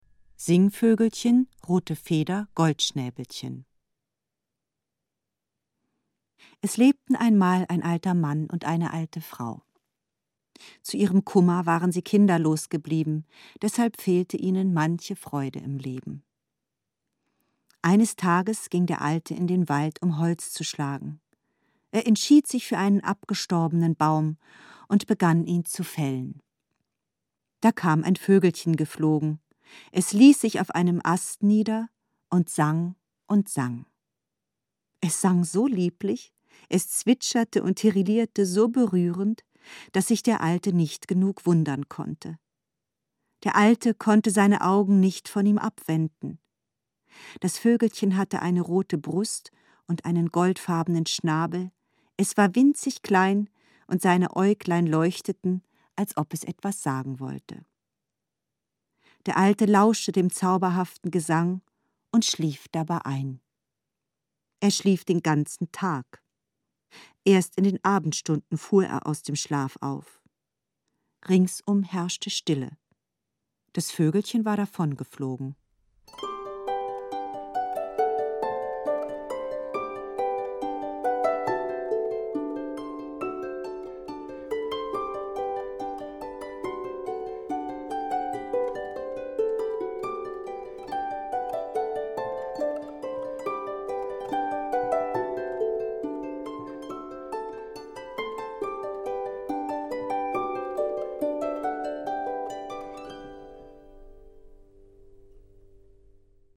Besondere Musik, Geschichten und Gedichte
Glasharfe
Harfe: Isabelle Moretti
Klavier und Celesta
Erzählerin
Erzähler: Christian Brückner